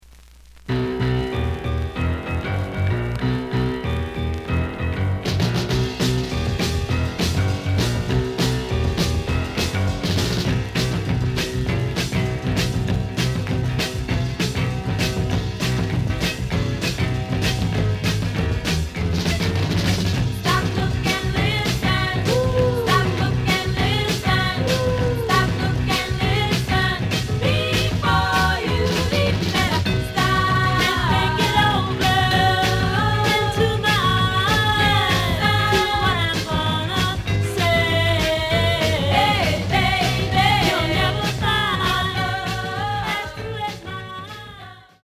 The audio sample is recorded from the actual item.
●Genre: Rhythm And Blues / Rock 'n' Roll
B side plays good.)